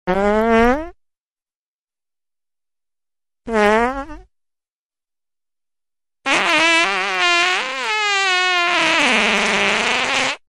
One Loooong Kitty Toot To Sound Effects Free Download